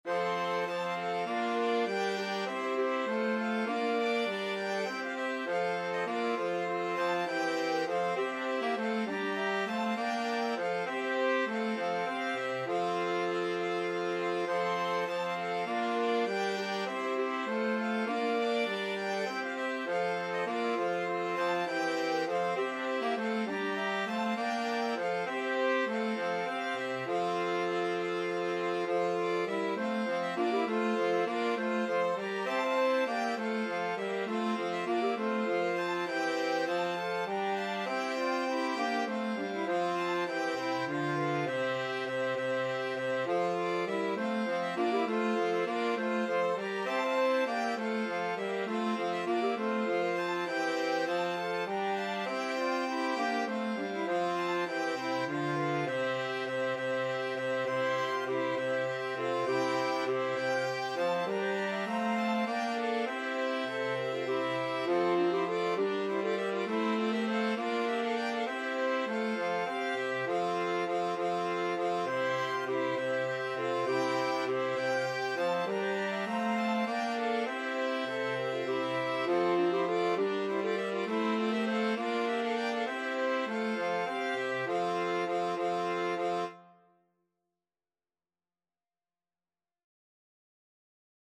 Violin
Clarinet
Trumpet
Tenor Saxophone
6/8 (View more 6/8 Music)